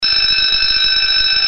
TIMBRE CAMPANA ELECTROMAGNÉTICO
100dB